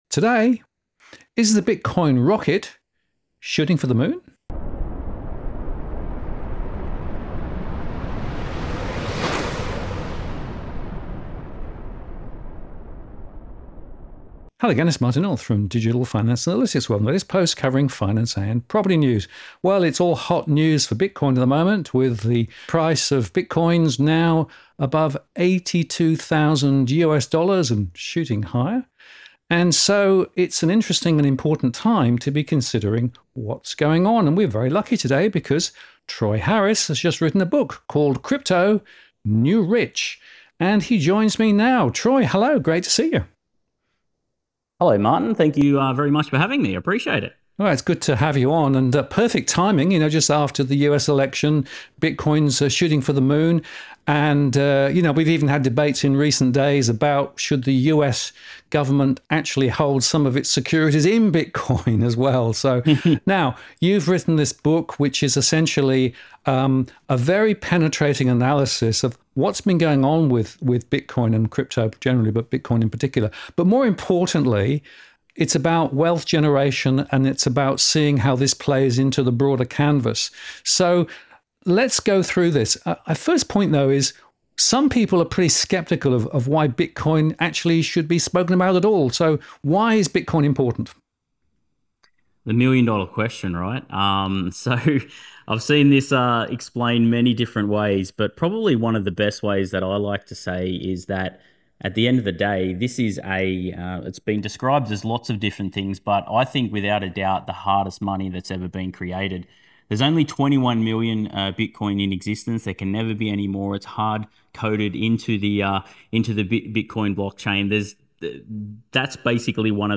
DFA Live Q&A HD Replay: After The Halving